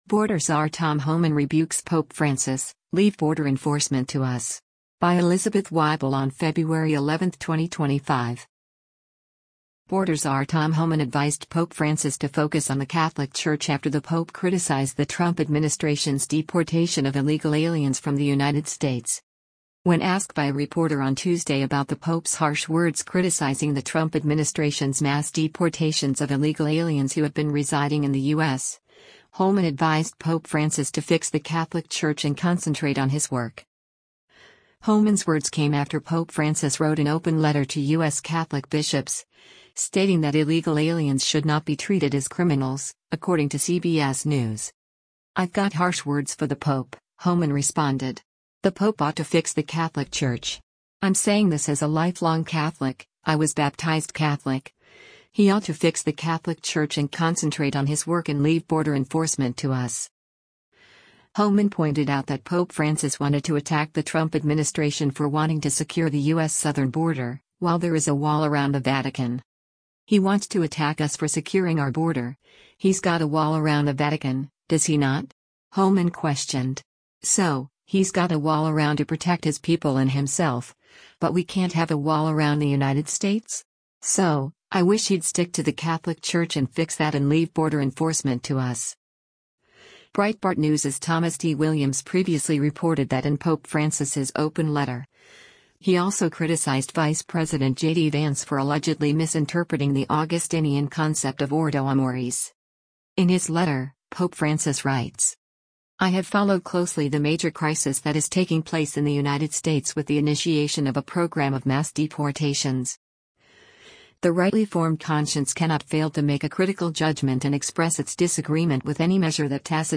White House 'border czar' Tom Homan speaks to the press outside the West Wing of the White